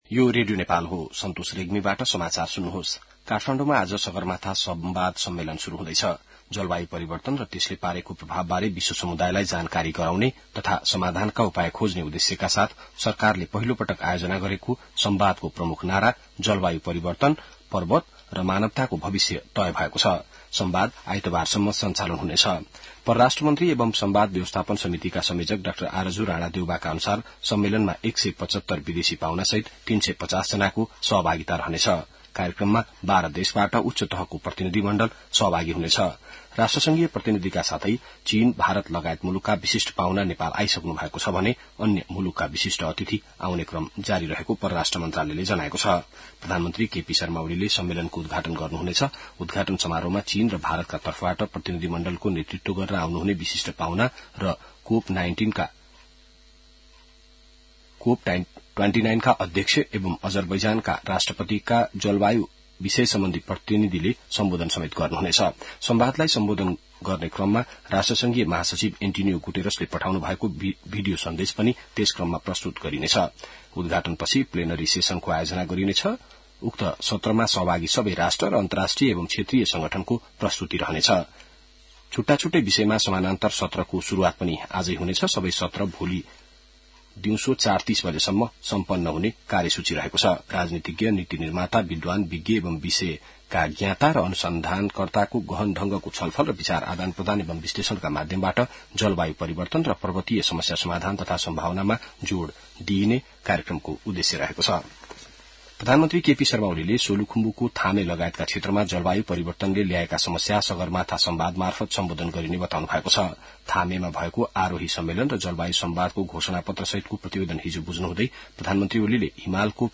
बिहान ६ बजेको नेपाली समाचार : २ जेठ , २०८२